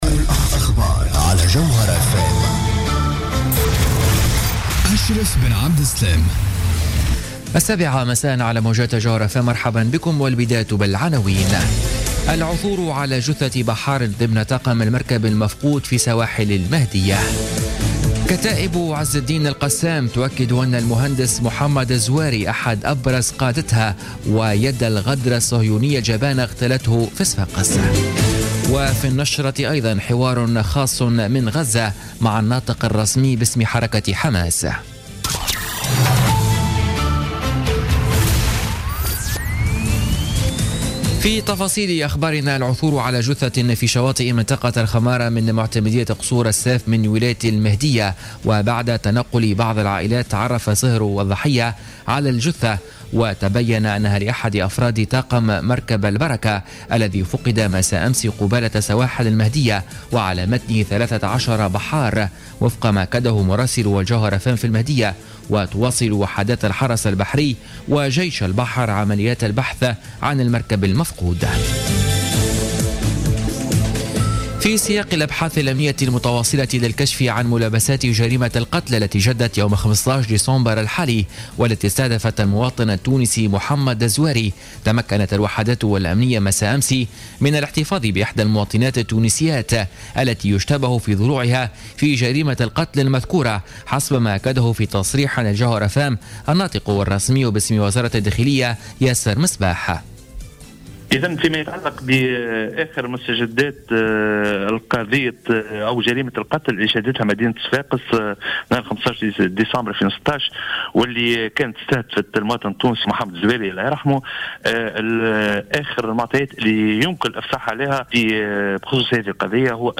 نشرة أخبار السابعة مساء ليوم السبت 17 ديسمبر 2016